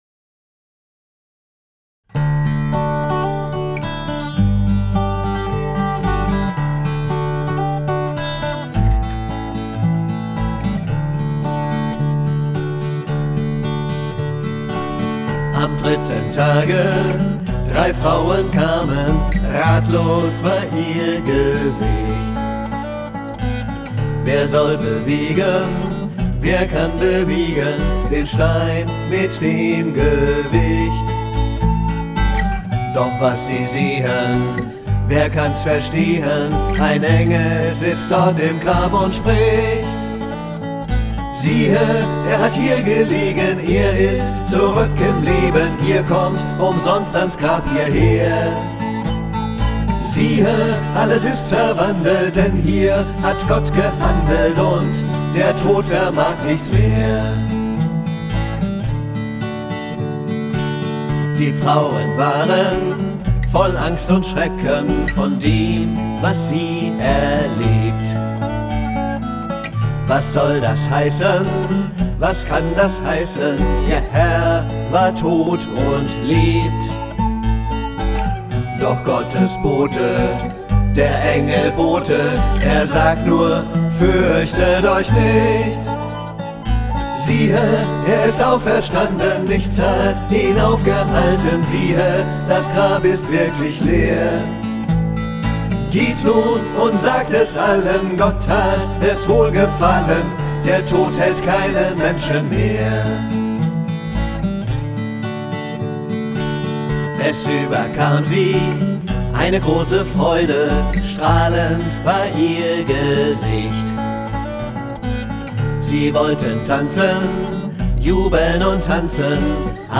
4/4 Takt, E-Dur, viel Text; Chorsatz verfügbar